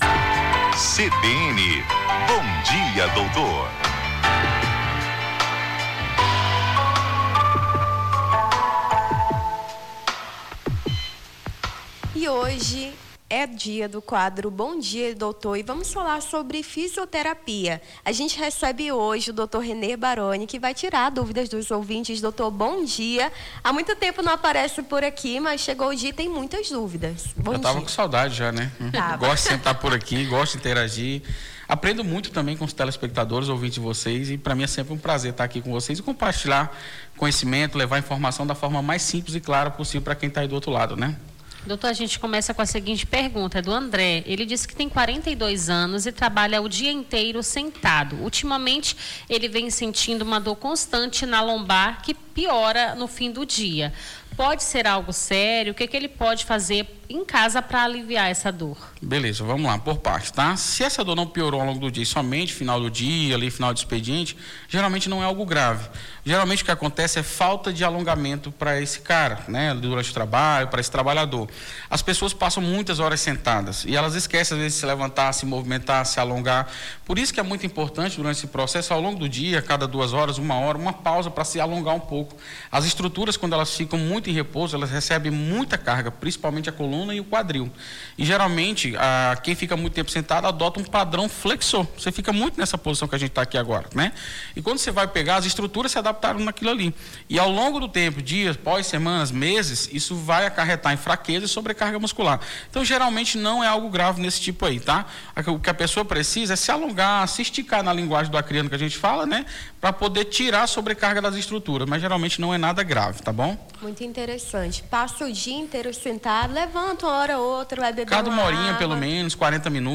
Bom dia, doutor: fisioterapeuta esclarece dúvidas dos ouvintes